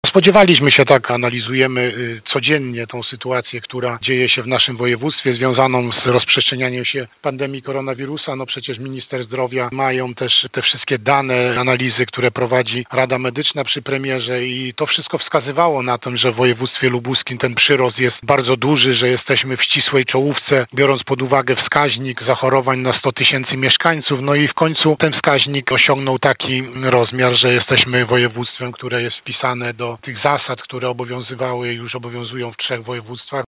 Analizy zakażeń wskazywały na możliwość wprowadzenia ograniczeń, i tak się stało – mówi wojewoda: